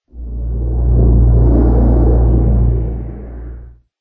ambient / cave / cave9.ogg